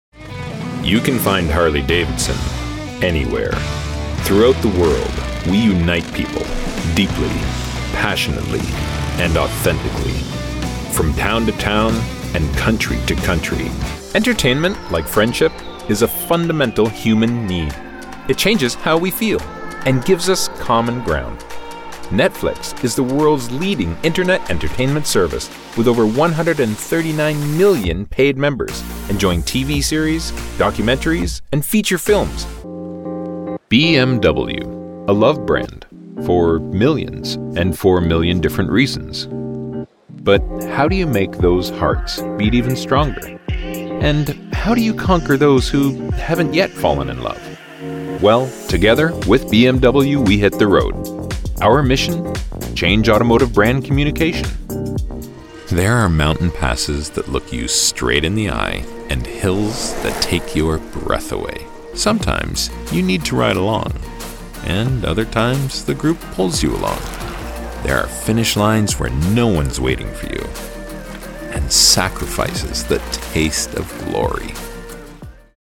a resonant, warm baritone voice with a neutral Canadian accent
Corporate Videos
My dedicated, broadcast-quality studio is Source Connect Certified* and features a Neumann TLM103 microphone, a Universal Audio Apollo X preamp, Audio Technical M50x Studio Headset, MacBook Pro running Adobe Audition, and a hard-wired ethernet connection with 1.5G speed.